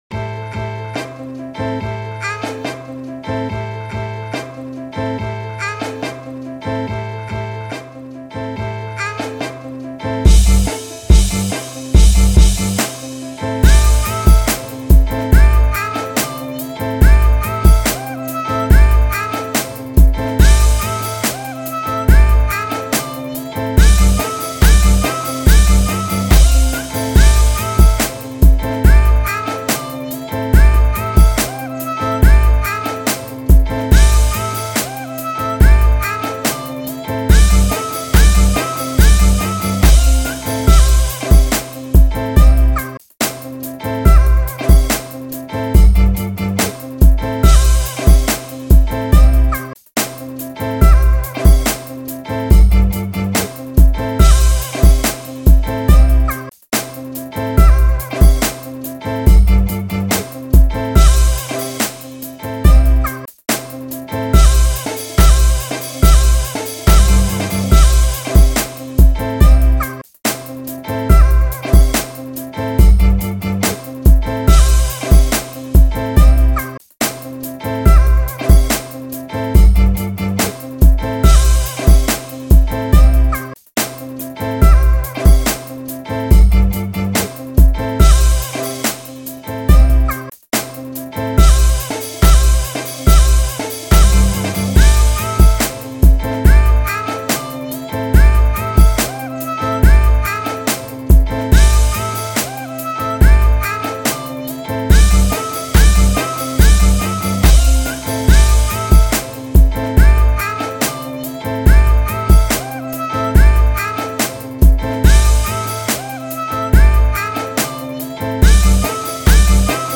Inst./HipHop